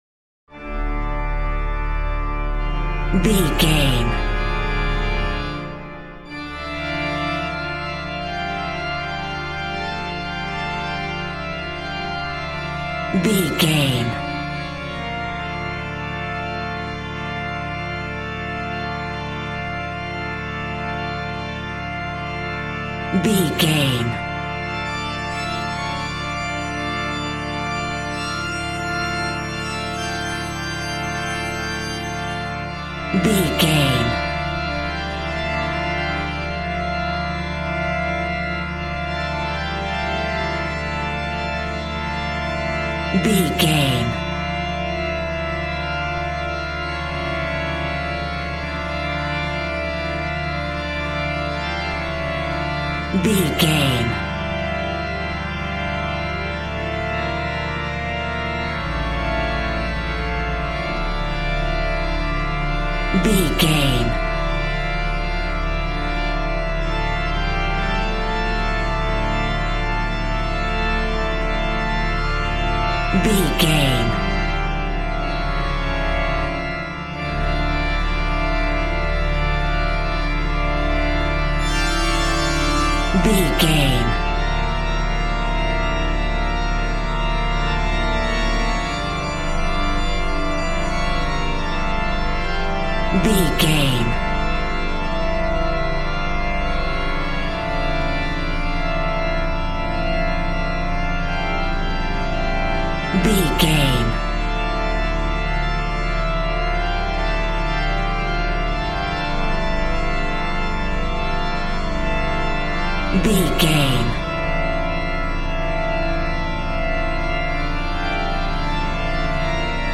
Aeolian/Minor
Slow
scary
tension
ominous
dark
haunting
eerie